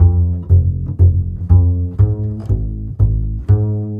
Rock-Pop 11 Bass 08.wav